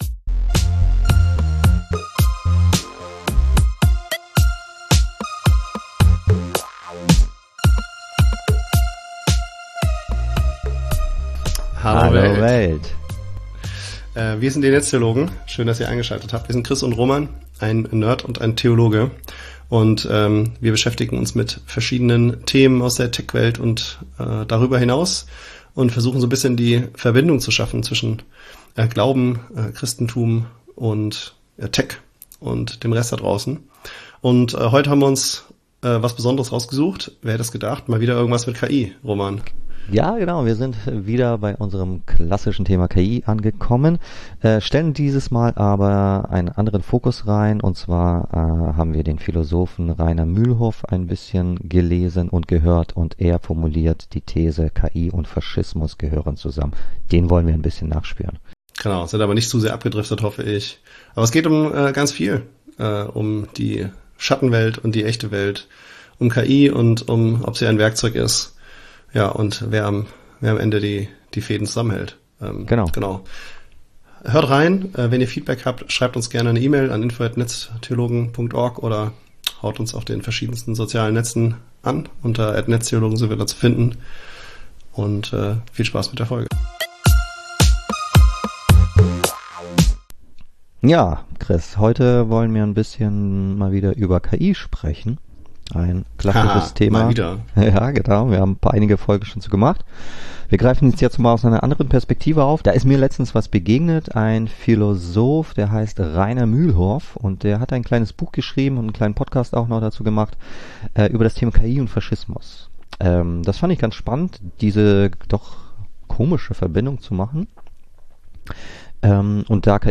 Ein Nerd und ein Theologe diskutieren den digitalen Wandel.